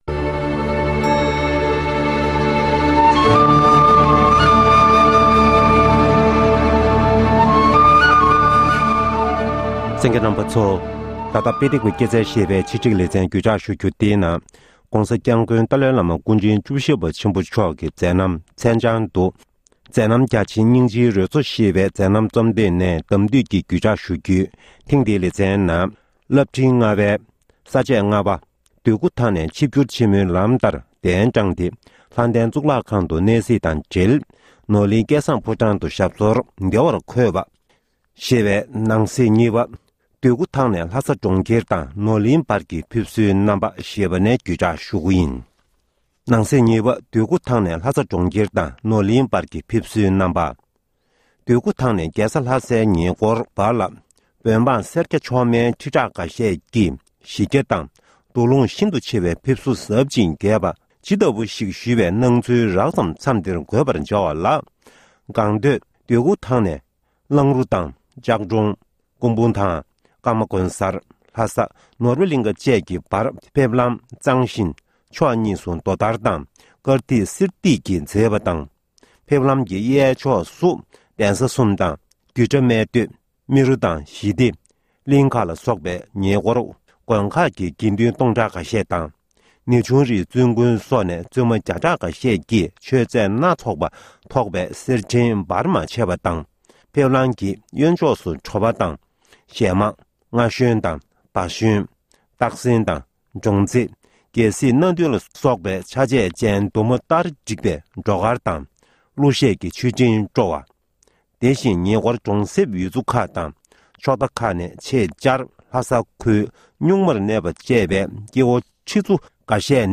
བཅའ་དྲི་གླེང་མོལ་